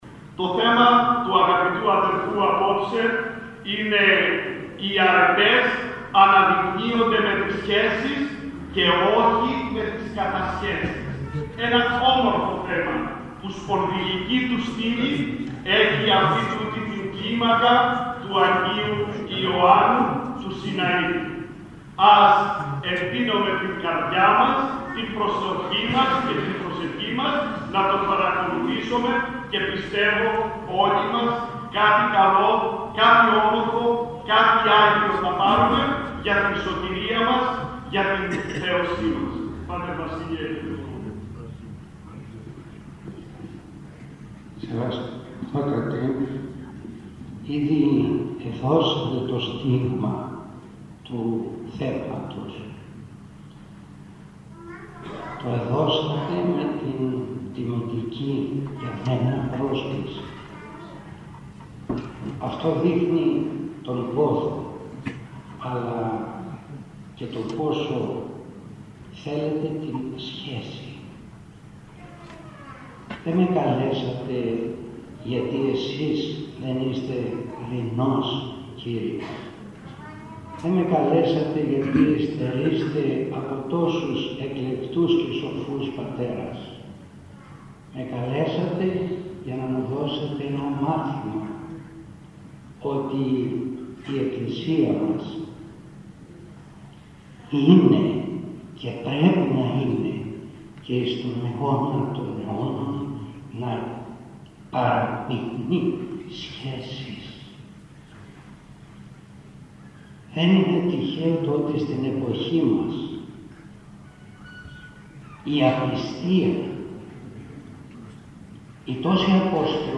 Ομιλίες περί σχέσεων – γάμου – οικογένειας